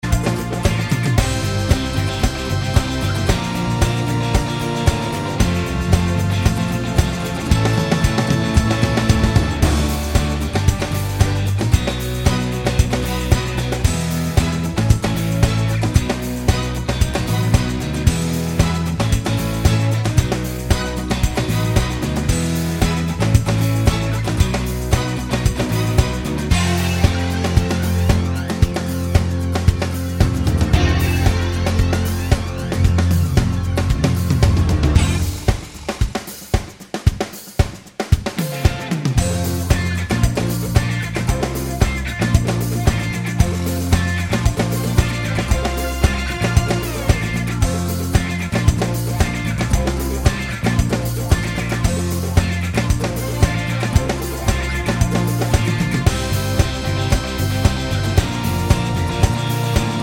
With Rapper Pop (1990s) 3:51 Buy £1.50